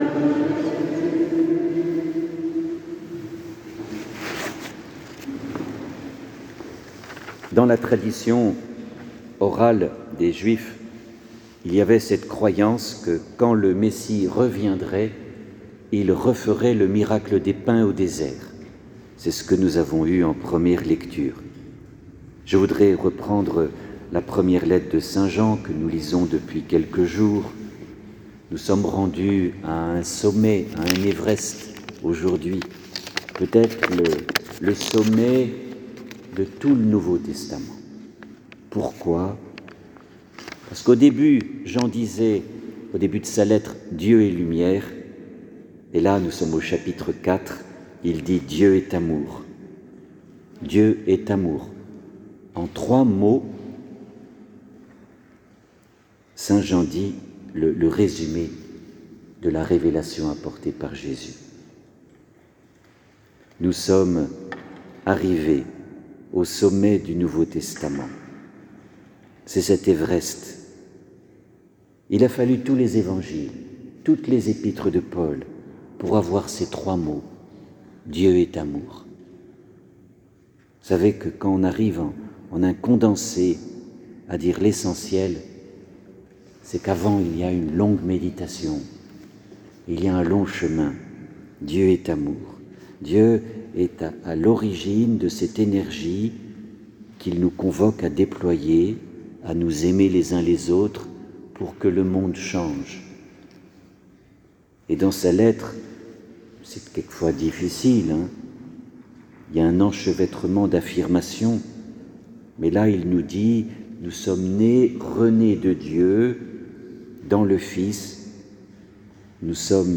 les homélies « Préparons notre Résurrection » – Eglise Saint Ignace